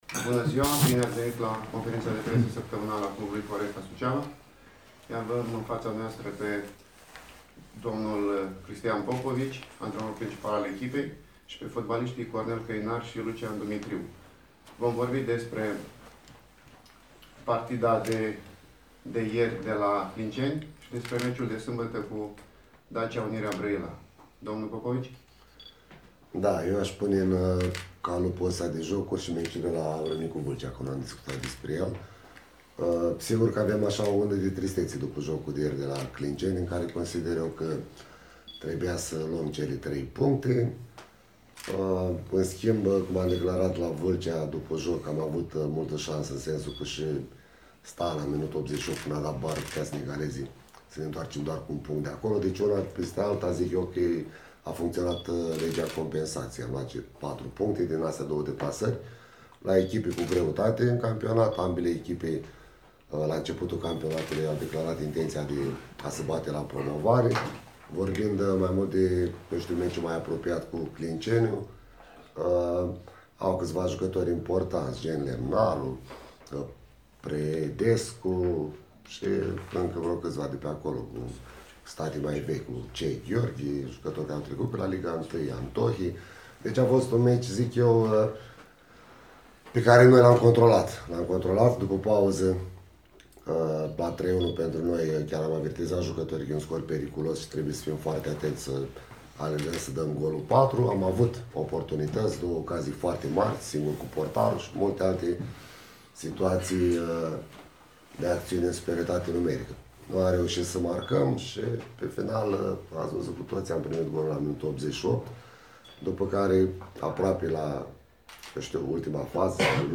Înregistrarea integrală a conferinţei de presă